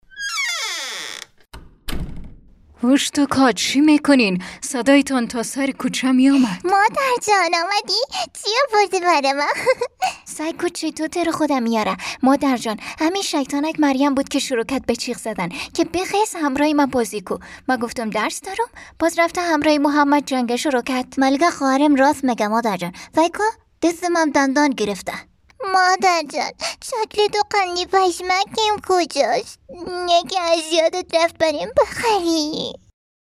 Female
Gril